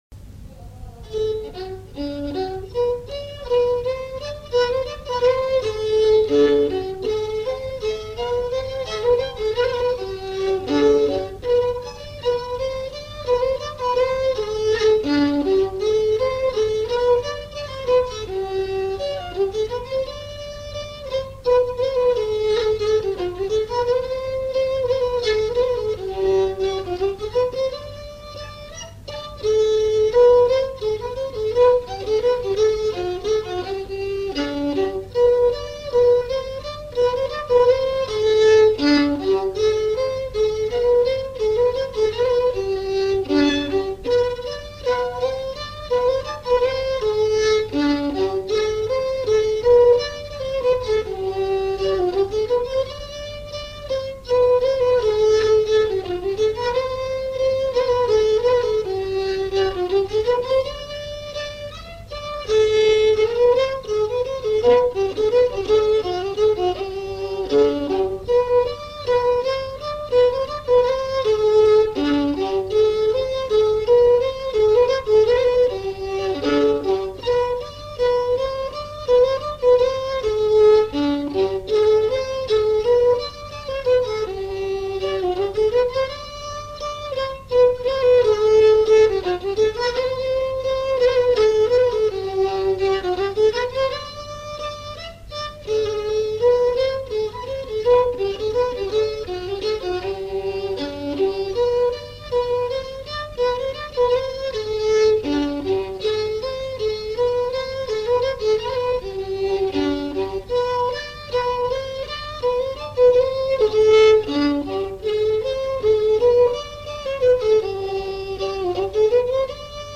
danse : java
enregistrements du Répertoire du violoneux
Pièce musicale inédite